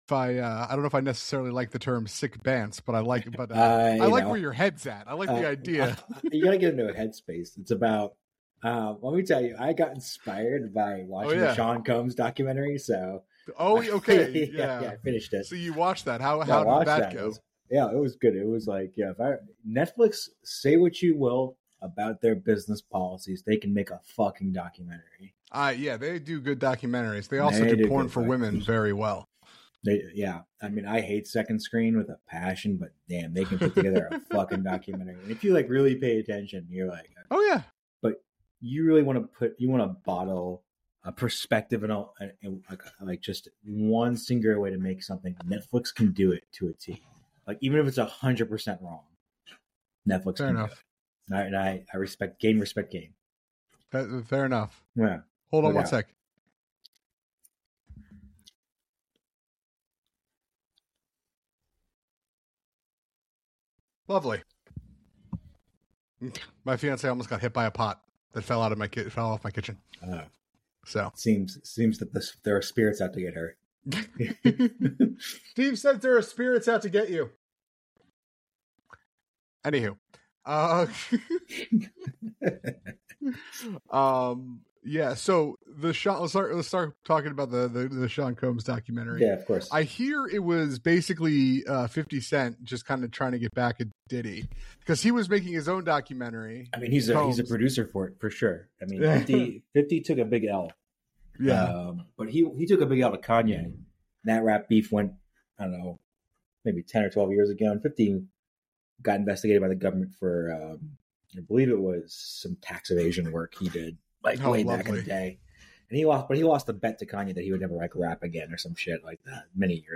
In this engaging conversation, the hosts delve into various topics, starting with a discussion on the Sean Combs documentary and its implications. They share personal holiday experiences and reflect on upcoming television shows, particularly the excitement surrounding ‘Pluribus’ and ‘Stranger Things’. The conversation touches on the themes of character development and controversies within ‘Stranger Things’, highlighting the emotional impact and narrative choices made in the series.